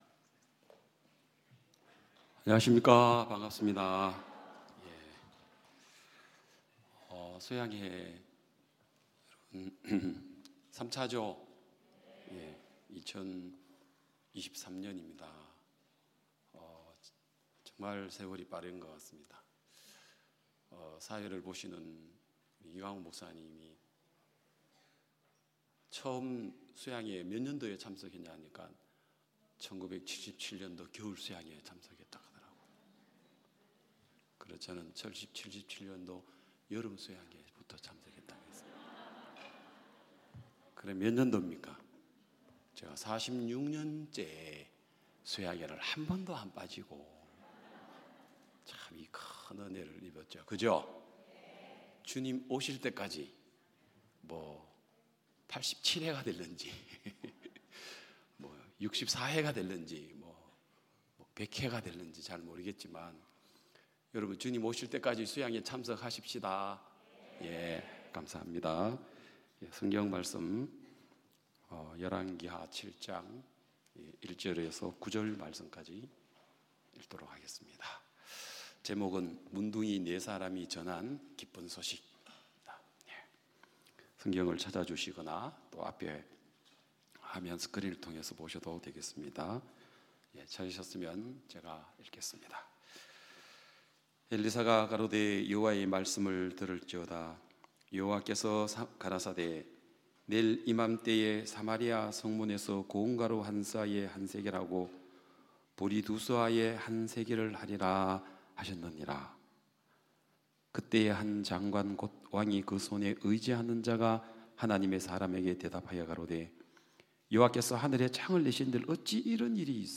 매년 굿뉴스티비를 통해 생중계 됐던 기쁜소식 선교회 캠프의 설교 말씀을 들어보세요.